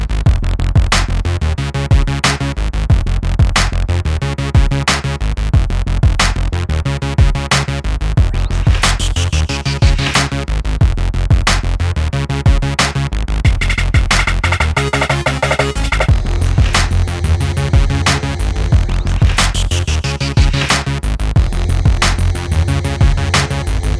for duet R'n'B / Hip Hop 4:29 Buy £1.50